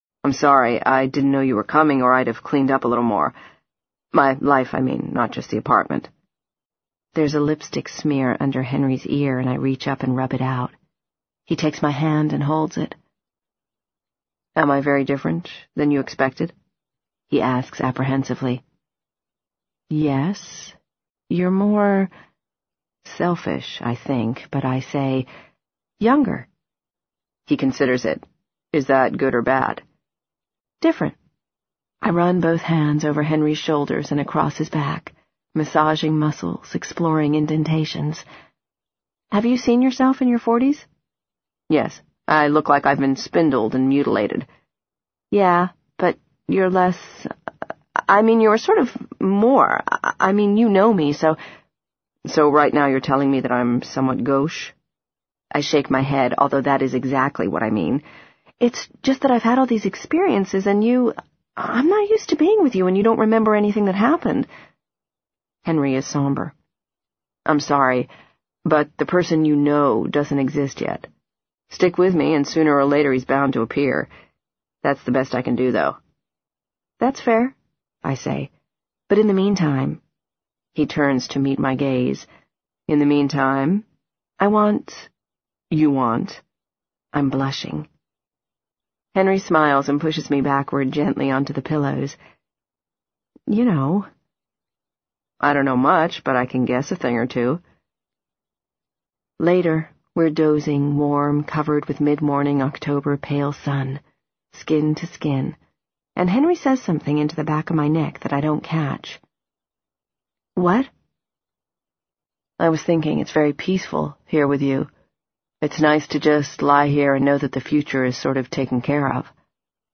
在线英语听力室【时间旅行者的妻子】19的听力文件下载,时间旅行者的妻子—双语有声读物—英语听力—听力教程—在线英语听力室